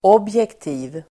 Uttal: ['åb:jekti:v (el. -'i:v)]